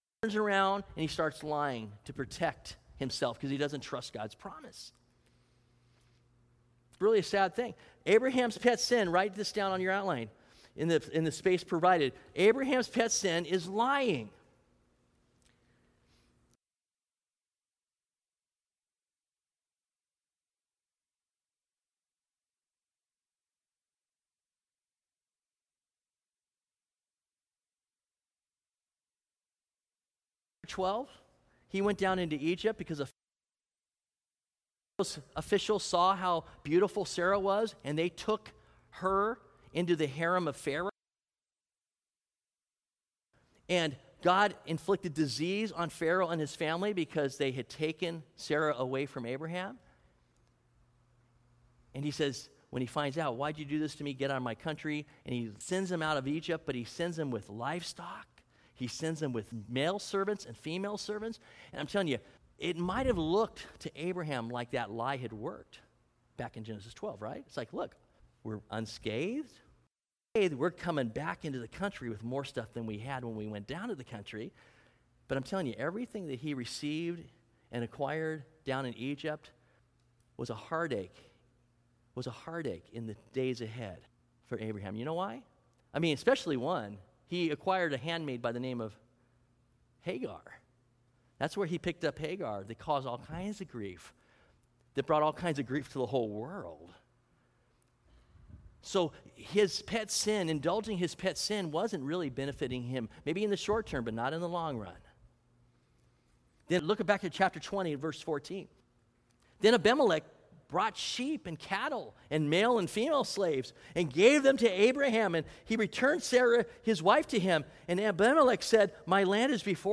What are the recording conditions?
Due to technical difficulties, the first 2 minutes of the sermon are missing.